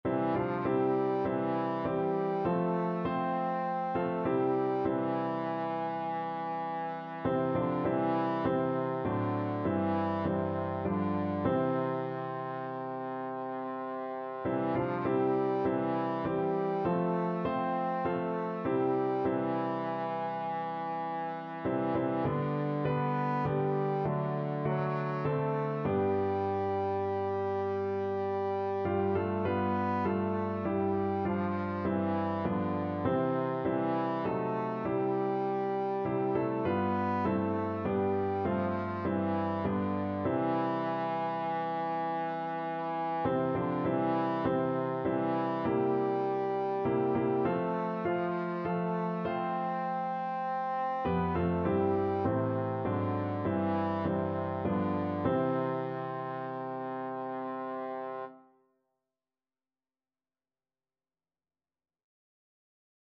Trombone
C major (Sounding Pitch) (View more C major Music for Trombone )
Classical (View more Classical Trombone Music)
garden_of_prayer_TBNE.mp3